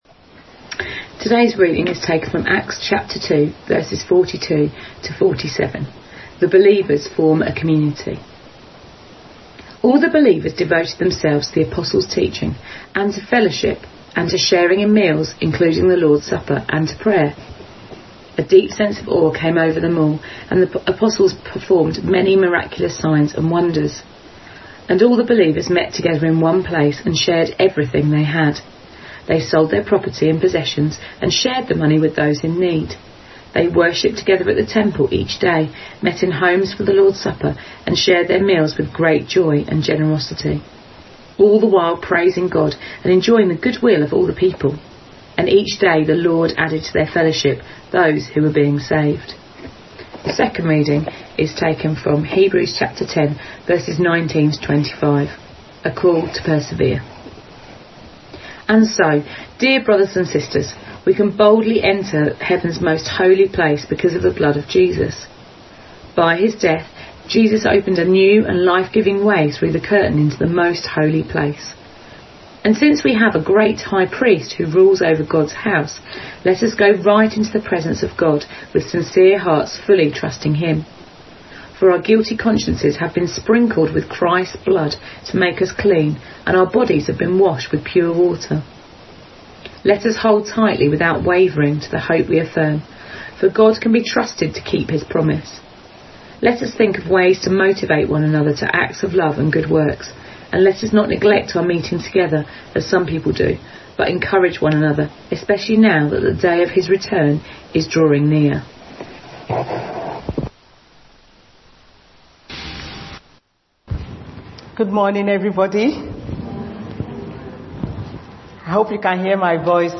A message from the series "Disciple Shift."